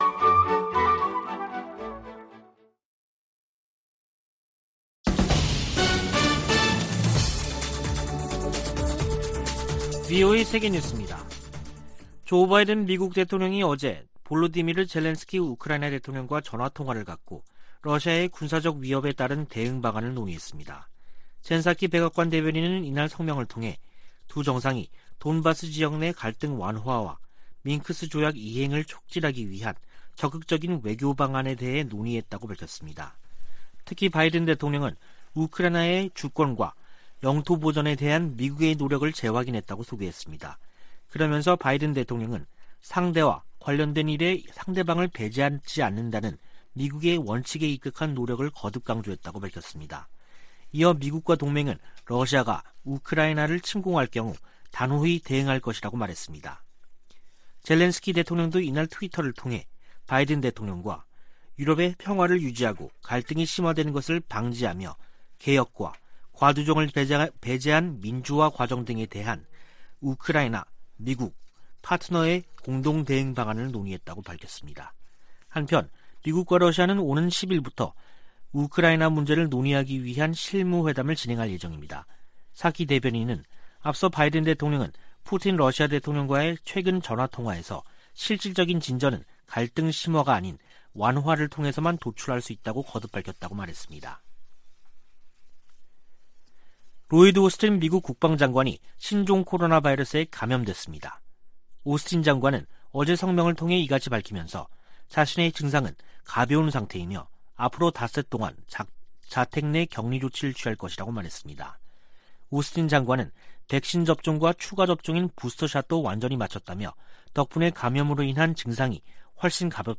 VOA 한국어 간판 뉴스 프로그램 '뉴스 투데이', 2022년 1월 3일 3부 방송입니다. 문재인 한국 대통령이 한반도 평화 제도화 노력을 멈추지 않겠다고 신년사에서 밝혔습니다. 조 바이든 미국 대통령이 역대 최대 규모의 국방예산을 담은 2022국방수권법안에 서명했습니다. 탈북민들은 새해를 맞아 미국 등 국제사회가 북한 인권 문제에 더 초점을 맞출 것을 희망했습니다.